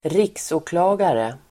Uttal: [²r'ik:så:kla:gare]